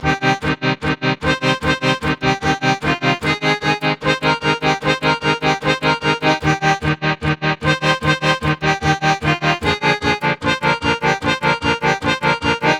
Gully-Loops-Barso-Drop-Loop-BPM-75-C-Min.wav